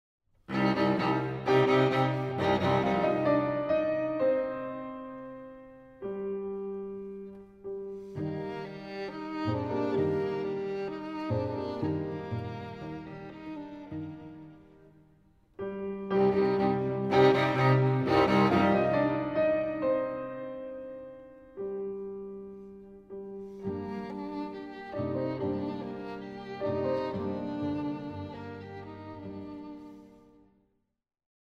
Piano Trio in E minor